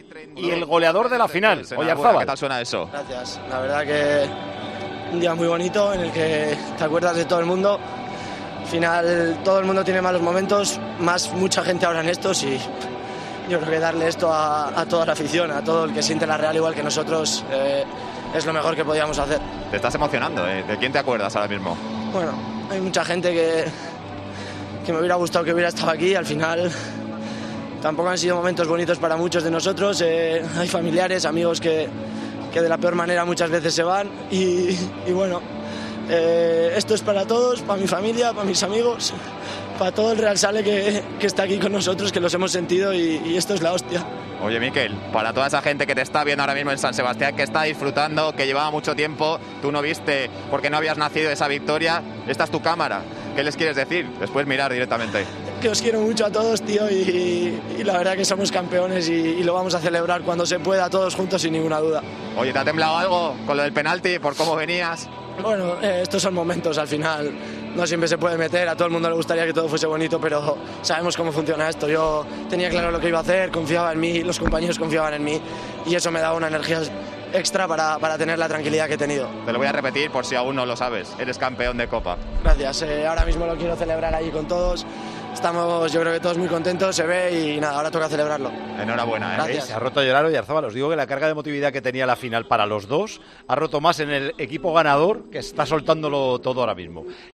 Las lágrimas de emoción de Oyarzabal al terminar la Final